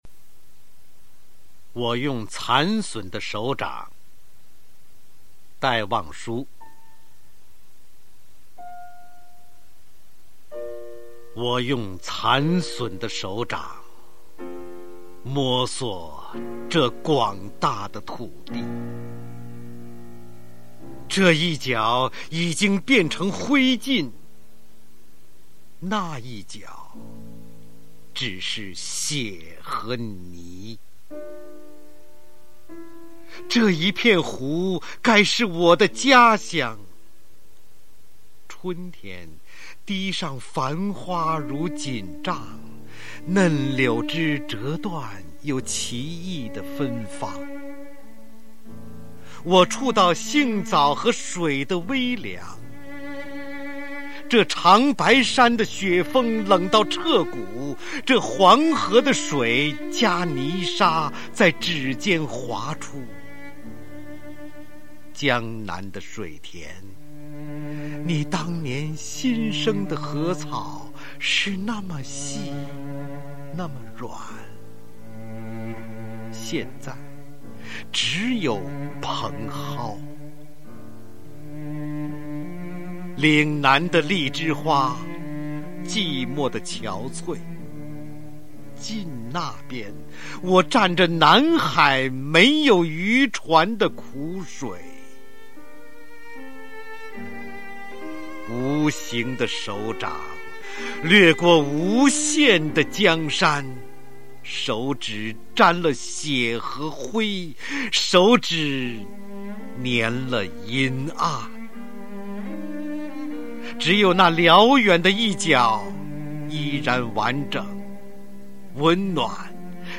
[15/9/2008]方明 现代诗歌配乐朗诵《我用残损的手掌》（320K MP3)
朗诵：方  明